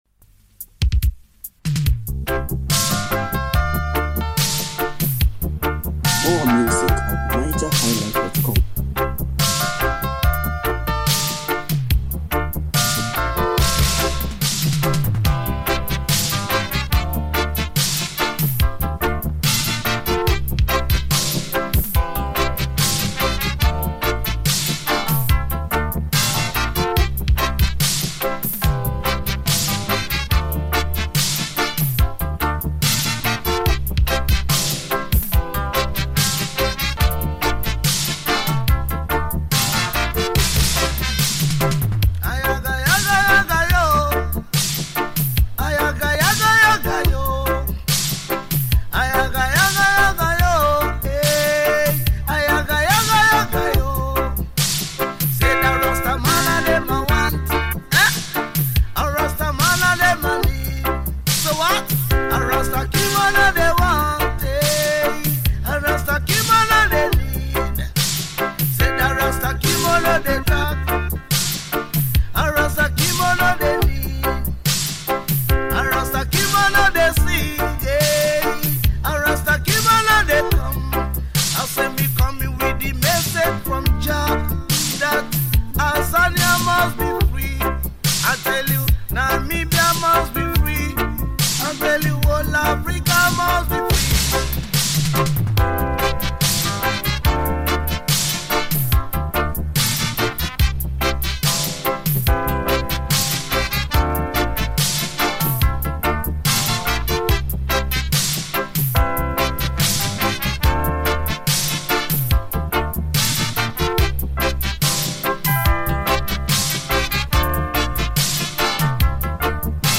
Home » Ragae
Nigerian Reggae Music